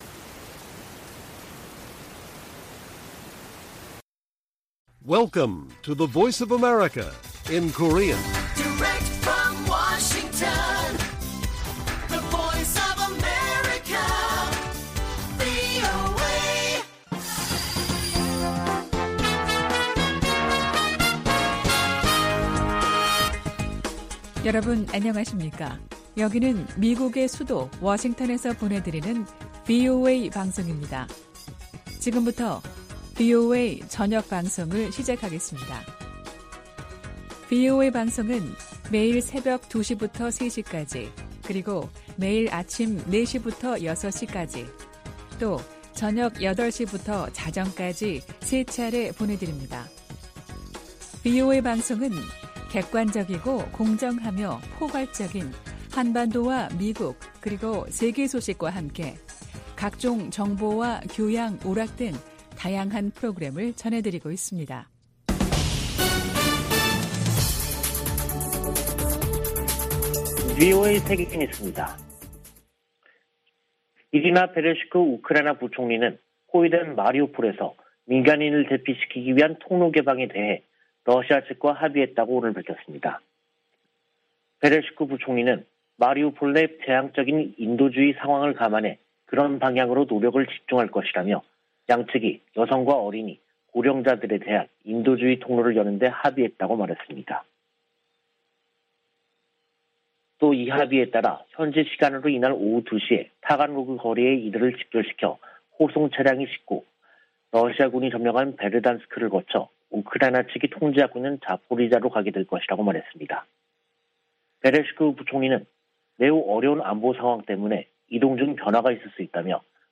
VOA 한국어 간판 뉴스 프로그램 '뉴스 투데이', 2022년 4월 20일 1부 방송입니다. 성 김 미 국무부 대북특별대표가 한국 윤석열 차기 정부의 박진 외교부 장관 후보자를 만나 북 핵 문제 등 공조 방안을 논의했습니다. 북한이 대량살상무기와 탄도미사일 개발 자금을 조달하기 위해 악의적 사이버 활동을 벌이고 있다고 백악관 당국자가 지적했습니다. 유럽연합은 북한의 신형 전술유도무기를 시험 주장과 관련해 강력한 제재 이행 기조를 확인했습니다.